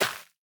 Minecraft Version Minecraft Version latest Latest Release | Latest Snapshot latest / assets / minecraft / sounds / block / rooted_dirt / break4.ogg Compare With Compare With Latest Release | Latest Snapshot